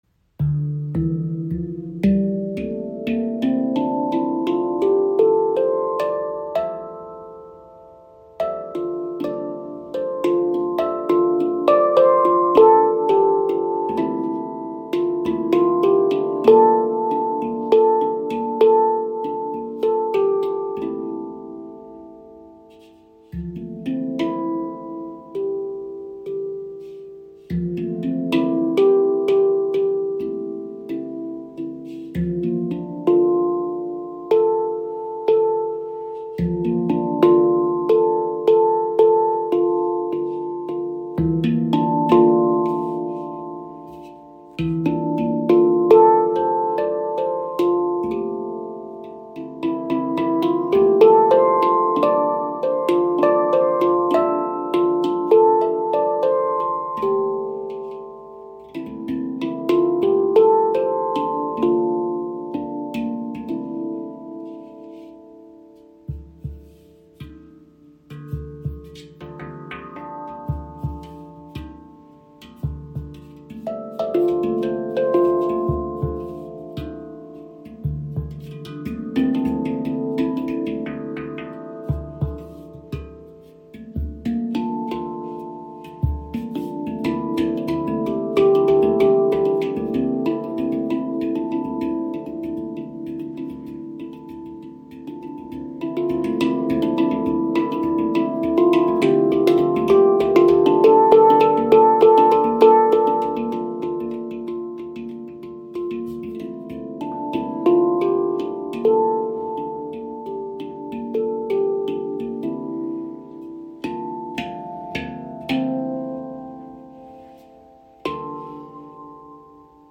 • Icon D Kurd – emotionaler Charakter (D – (E F G) A Bb C D E F G A C D E)
Gefertigt aus Ember Steel, überzeugt die ShaktiPan durch eine warme, lange tragende Schwingung und einen besonders weichen, sensiblen Anschlag.
Die D-Kurd-Stimmung verbindet einen mollartigen Charakter mit einer geheimnisvollen, leicht orientalischen Klangfarbe.
Handpan aus Ember Steel. Geheimnisvolle, leicht orientalische Klangfarbe mit warmer, lang anhaltender Schwingung und feinem Anschlag – ideal für Klangbäder und emotionales Spiel.